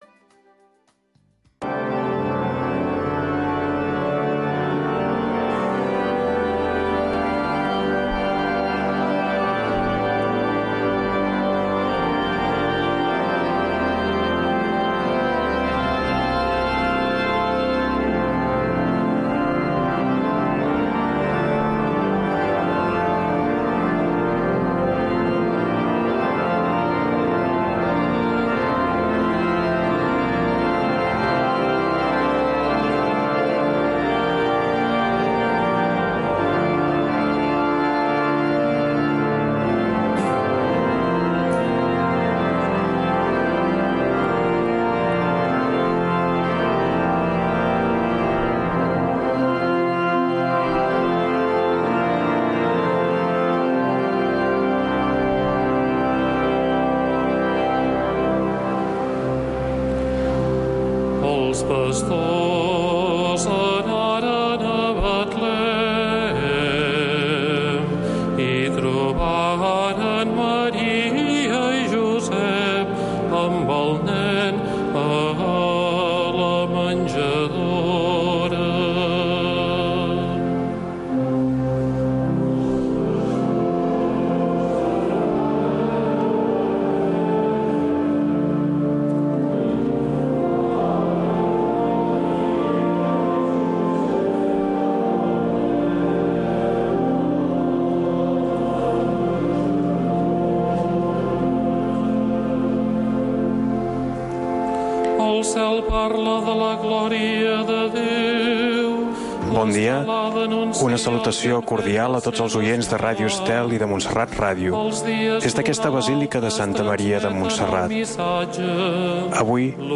Retransmissió en directe de la missa conventual des de la basílica de Santa Maria de Montserrat.